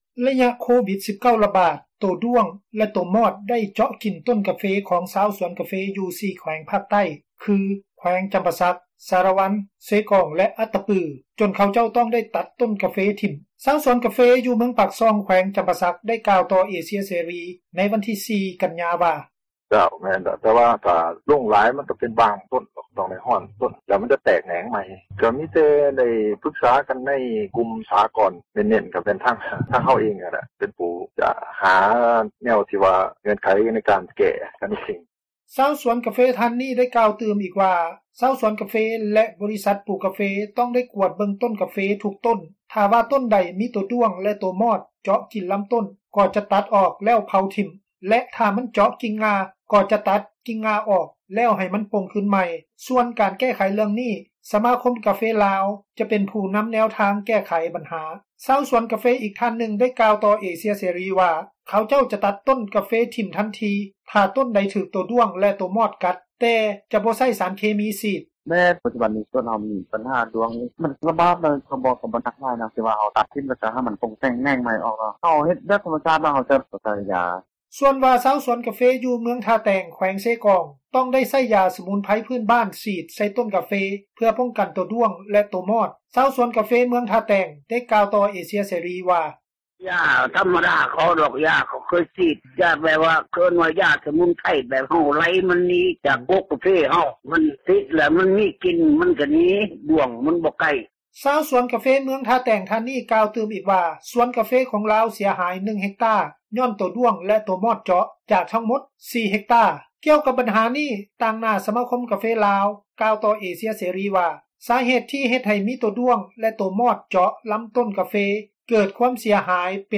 ໄລຍະໂຄວິດ19 ຣະບາດ, ໂຕດ້ວງ ແລະ ໂຕມອດ ໄດ້ເຈາະກິນຕົ້ນກາເຟ ຂອງຊາວສວນກາເຟຢູ່ 4 ແຂວງພາກໃຕ້ຄື ແຂວງຈຳປາສັກ, ສາຣະວັນ, ເຊກອງ ແລະ ອັດຕະປື ຈົນເຂົາເຈົ້າ ຕ້ອງໄດ້ຕັດຕົ້ນກາເຟຖິ້ມ. ຊາວສວນກາເຟ ຢູ່ເມືອງປາກຊ່ອງ ແຂວງຈຳປາສັກ ໄດ້ກ່າວ ຕໍ່ເອເຊັຽ ເສຣີ ໃນວັນທີ 4 ກັນຍາ ວ່າ:
ຊາວສວນ ກາເຟ ອີກທ່ານນຶ່ງກ່າວຕໍ່ ເອເຊັຽເສຣີ ວ່າເຂົາເຈົ້າຈະຕັດຕົ້ນກາເຟຖິ້ມທັນທີ ຖ້າວ່າ ຕົ້ນໃດຖືກໂຕດ້ວງ ແລະ ໂຕມອດ ກັດ, ແຕ່ຈະບໍ່ໃຊ້ສານເຄມີສີດ: